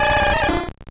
Cri de Carapuce dans Pokémon Or et Argent.